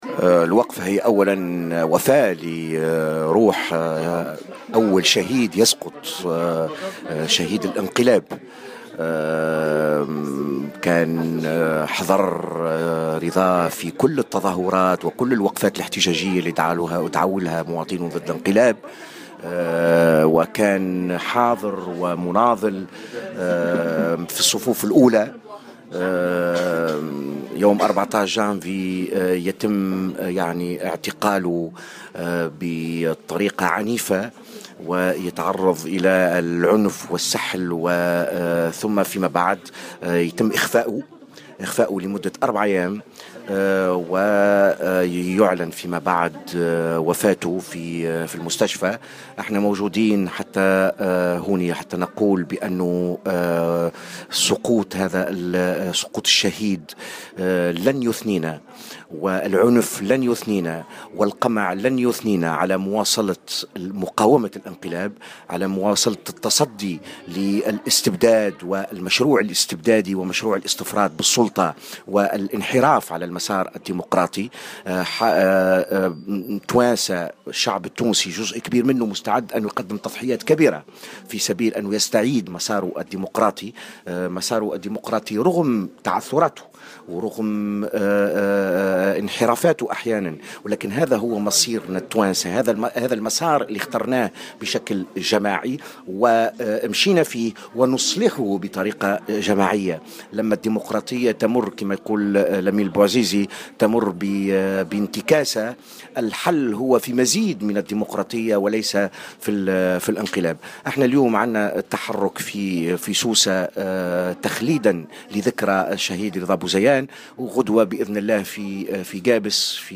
في تصريح للجوهرة أف أم، على هامش تحرك احتجاجي نظمته حملة "مواطنون ضد الانقلاب"